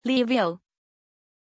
Aussprache von Lyvio
pronunciation-lyvio-zh.mp3